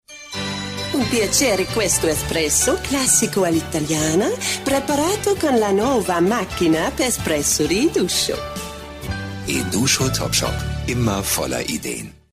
Werbung - Eduscho italienisch